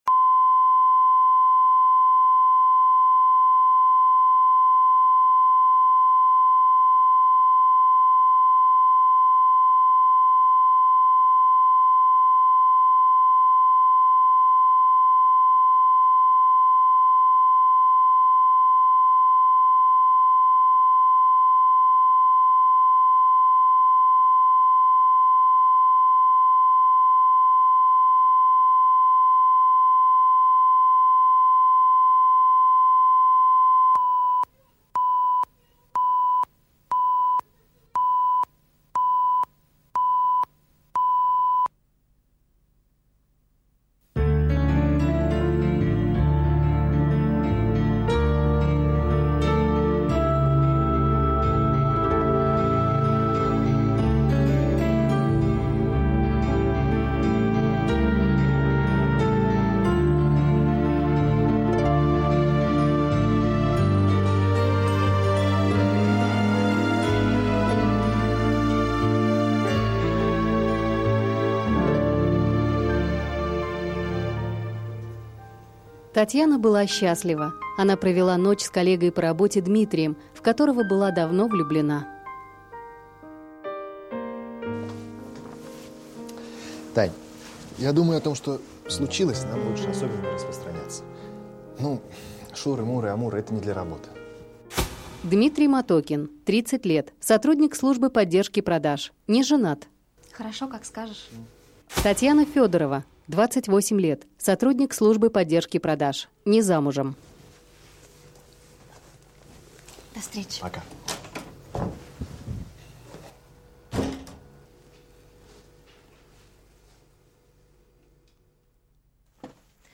Аудиокнига Карьеристы